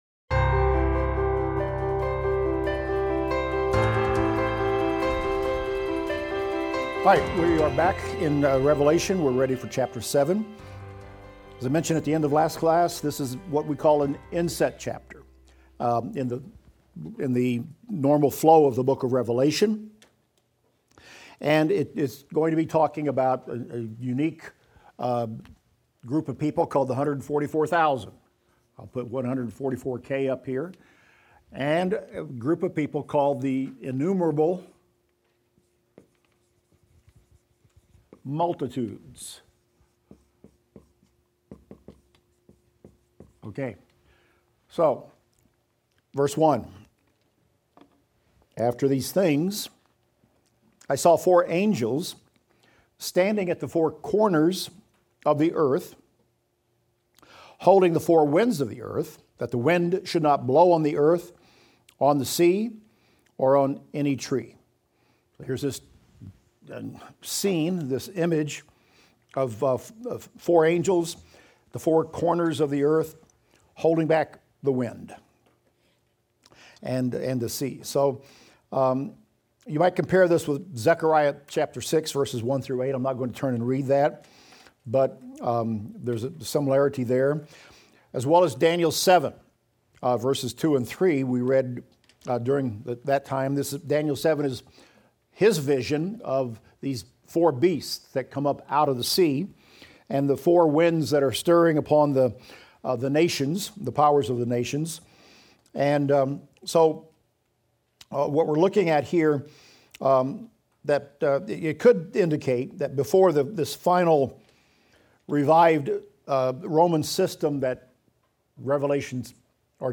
Revelation - Lecture 38 - Audio.mp3